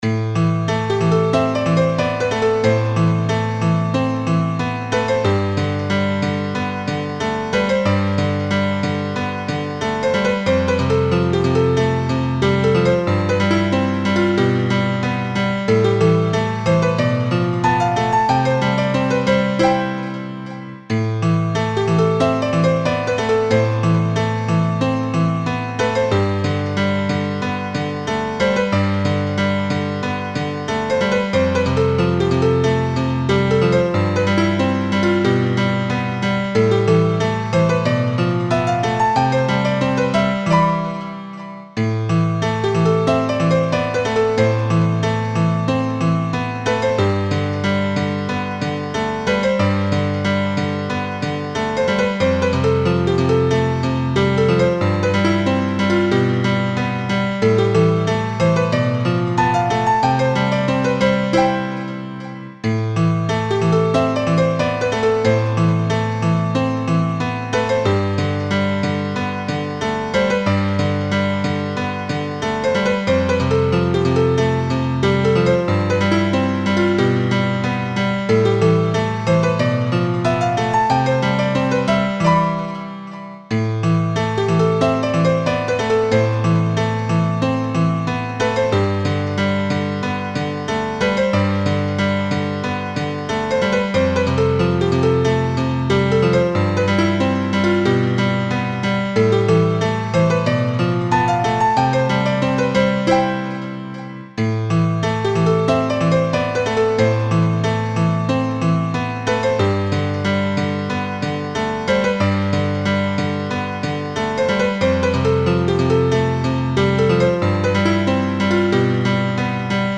音楽ジャンル： ヒーリング
楽曲の曲調： SOFT
悲劇的なシーンのBGM等に